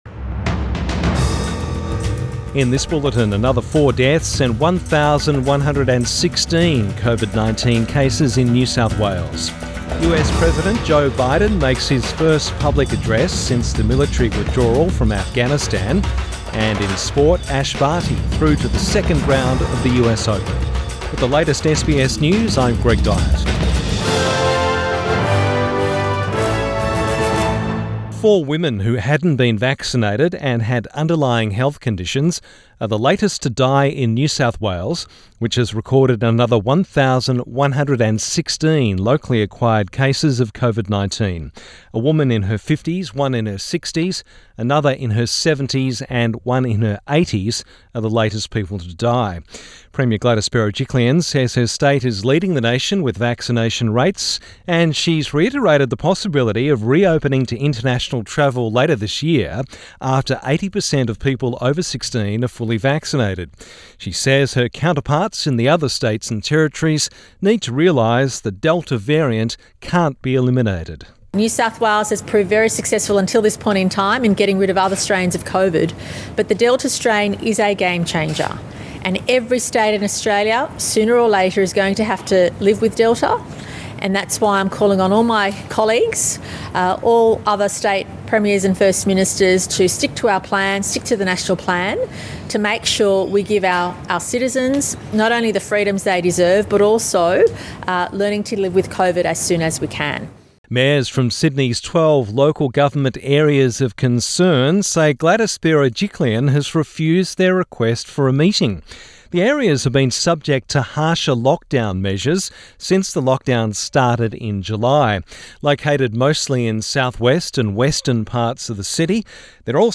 Midday bulletin 1 September 2021